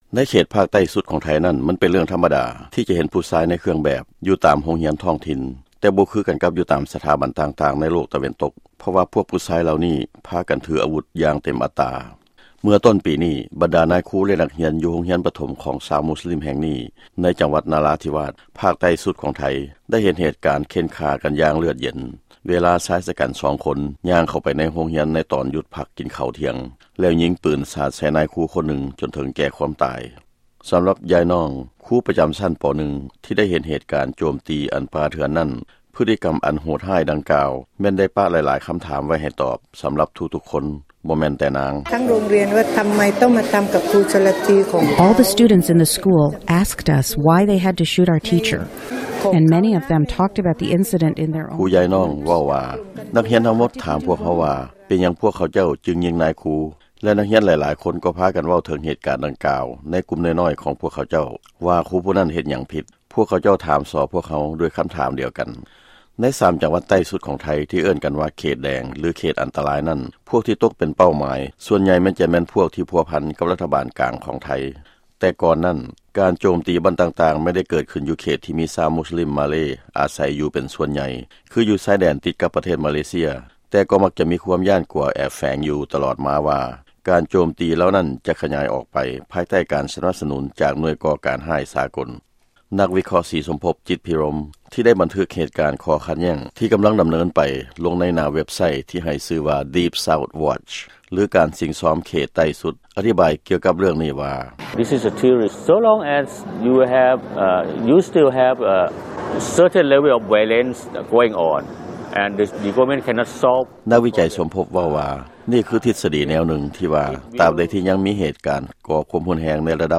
ຟັງລາຍງານກ່ຽວກັບພາກໃຕ້ສຸດຂອງໄທ